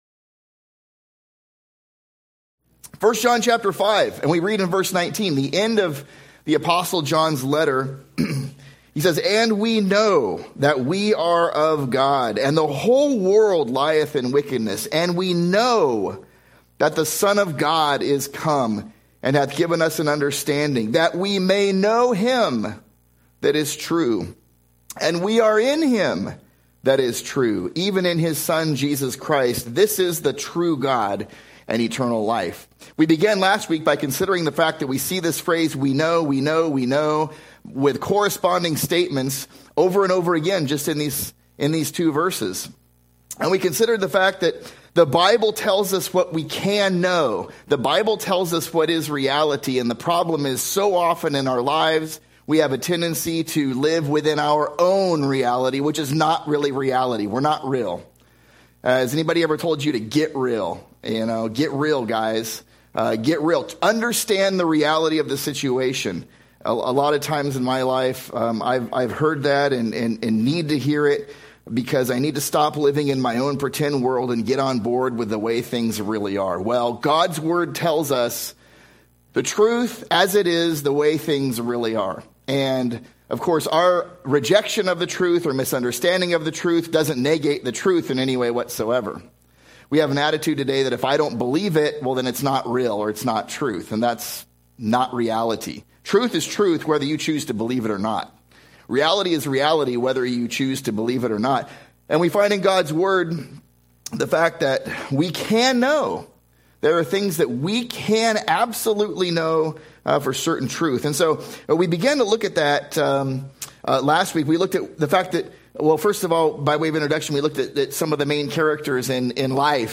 (pt. 2) – Grace Bible Church of Fresno